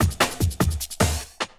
50 LOOP02 -R.wav